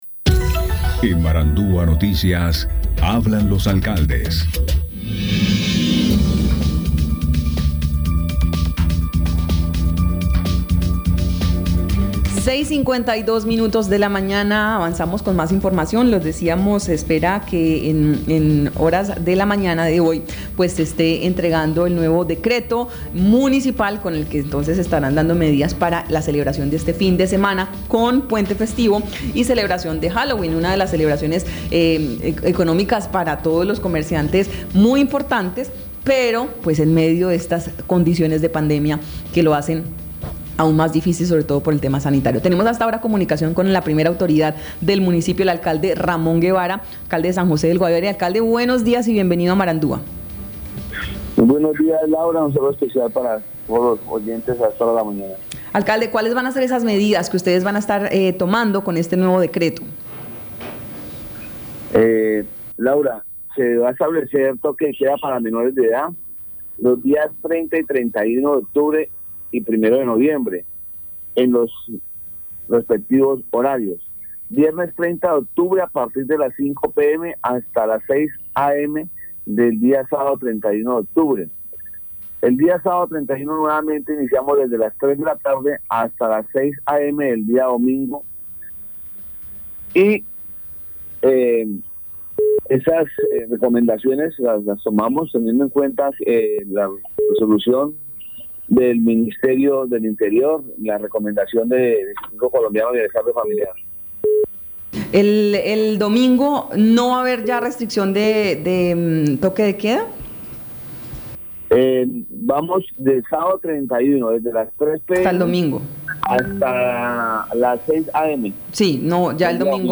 Escuche a Ramón Guevara Gómez, alcalde de San José del Guaviare.